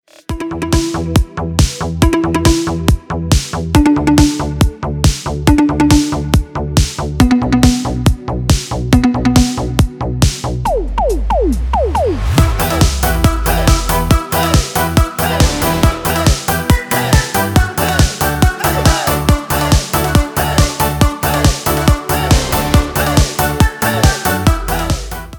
Веселые мелодии